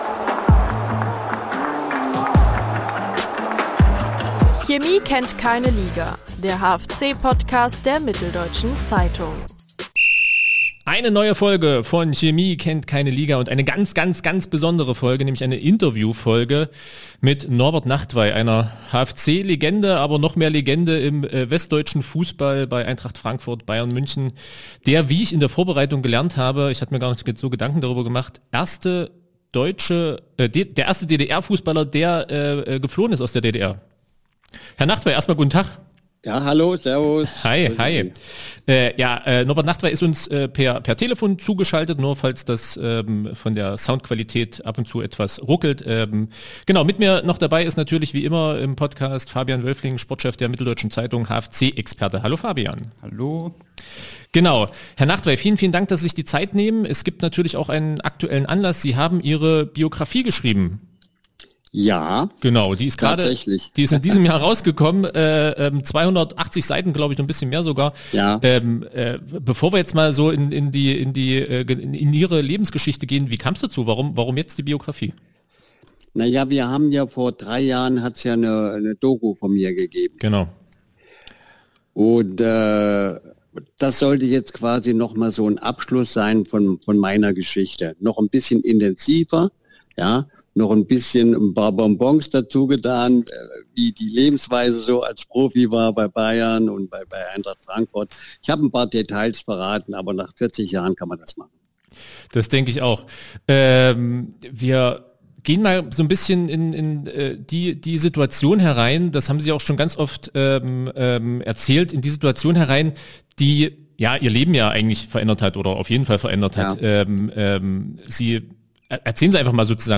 Interview mit Norbert Nachtweih über seine Flucht, die Stasi, Bernd Bransch und sein Gehalt beim FC Bayern ~ Chemie kennt keine Liga Podcast
In „Chemie kennt keine Liga“, dem HFC-Podcast der Mitteldeutschen Zeitung, erzählt Norbert Nachtweih in einem langen Interview auch von den Tagen der Flucht in der Türkei.